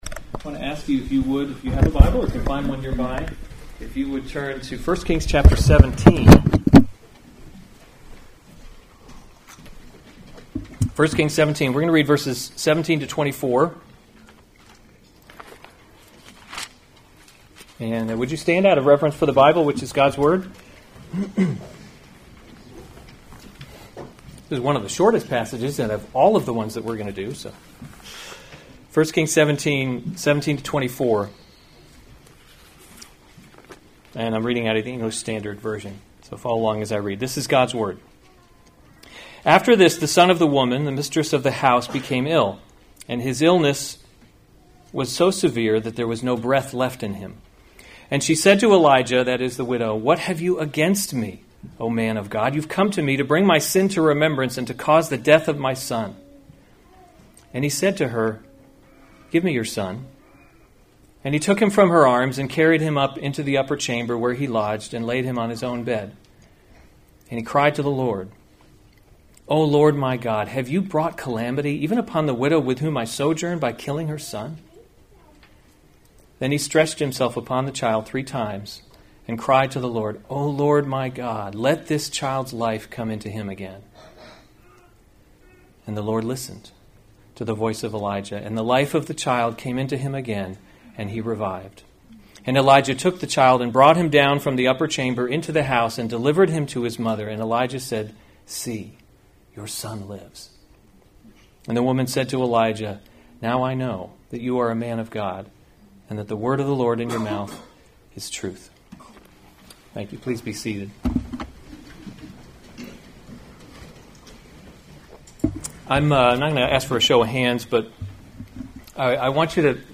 May 18, 2019 1 Kings – Leadership in a Broken World series Weekly Sunday Service Save/Download this sermon 1 Kings 17:17-24 Other sermons from 1 Kings Elijah Raises the Widow’s […]